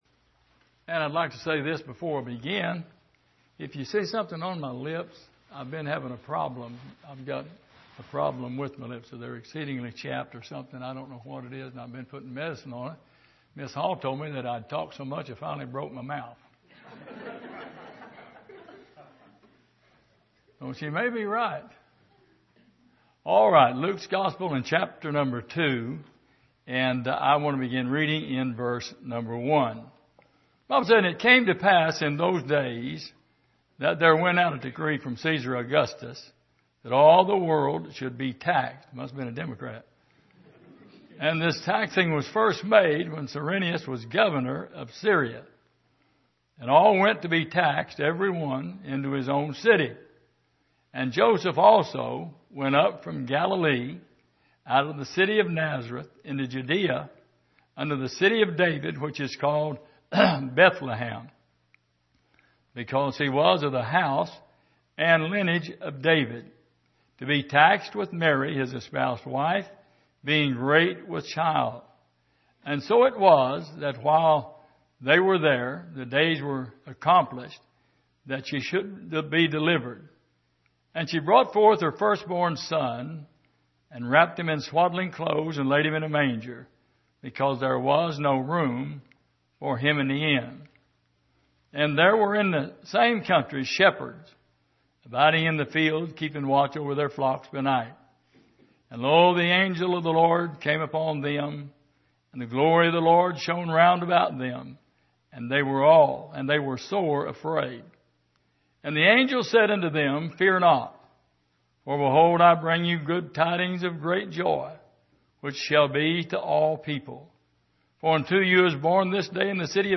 Service: Sunday Morning